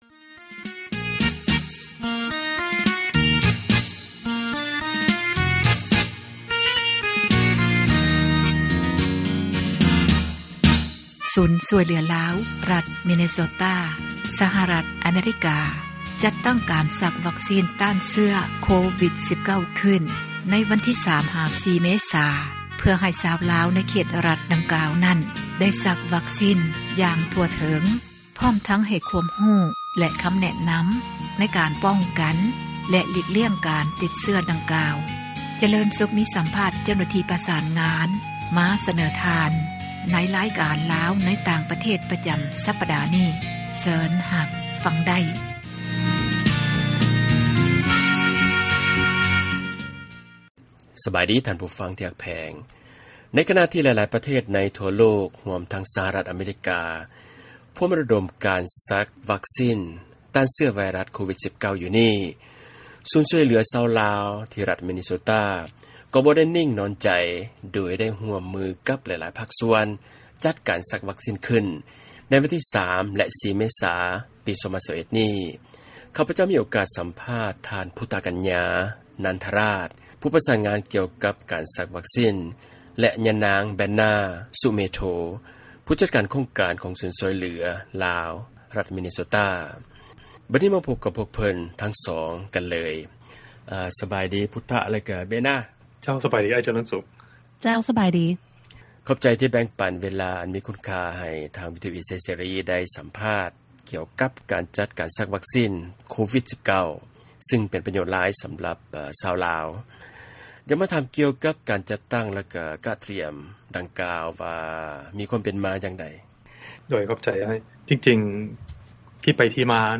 ວິທຍຸເອເຊັຽເສຣີ ສັມພາດ